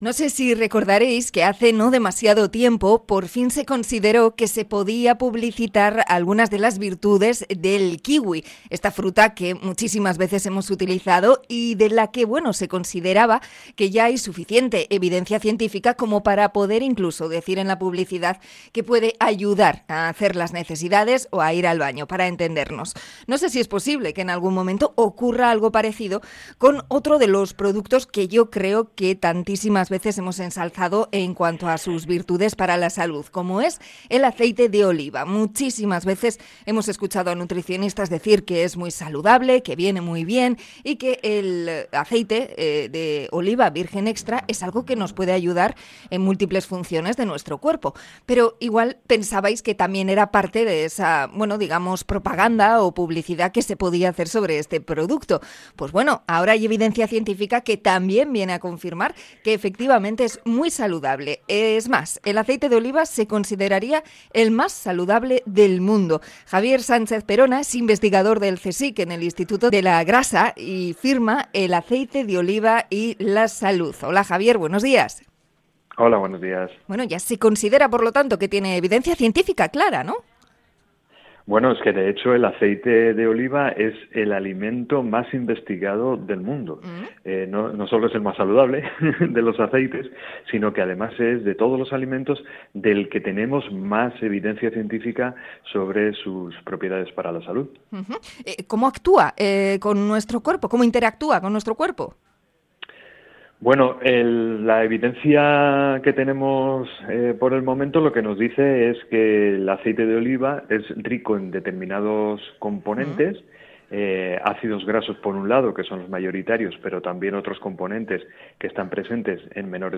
Entrevista sobre los aceites con investigador del CSIC